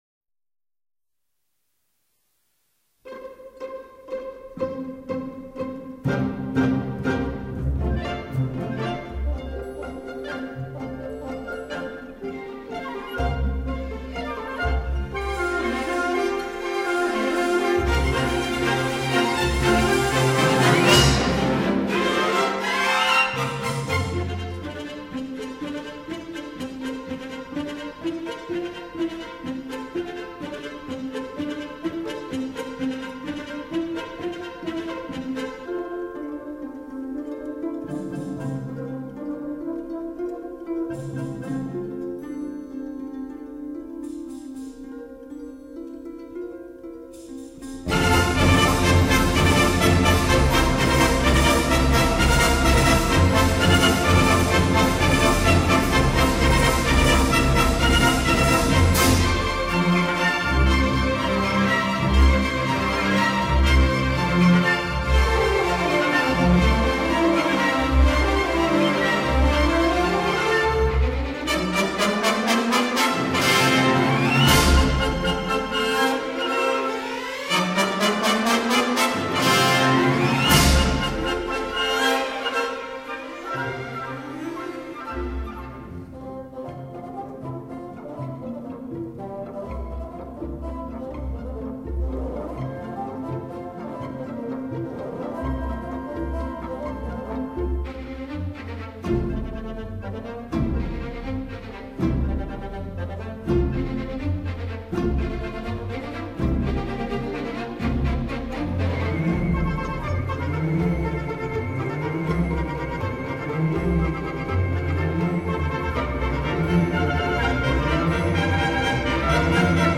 这些音乐管弦乐色彩异常丰富，效果瑰丽，节奏微妙多变，旋律充满拉丁情调